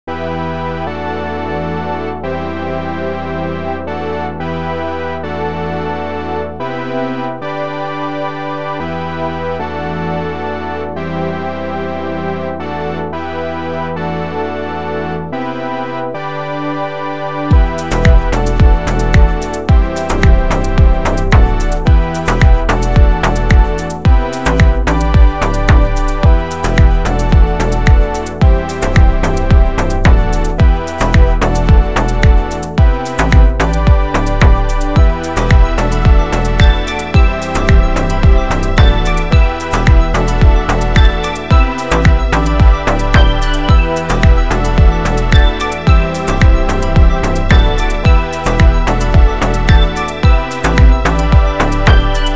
Pop, Dance
E Minor